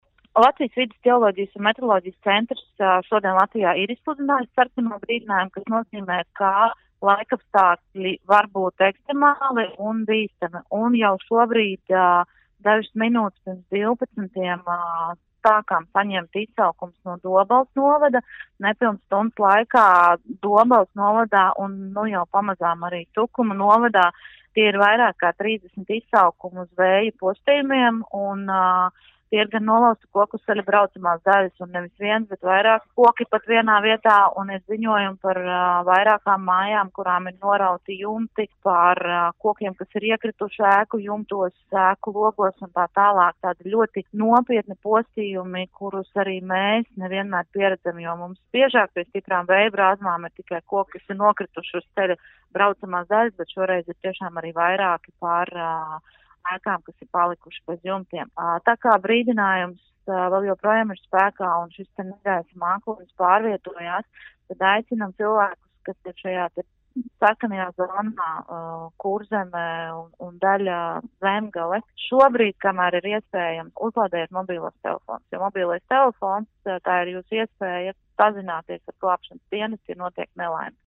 RADIO SKONTO Ziņās par izsludināto sarkano brīdinājumu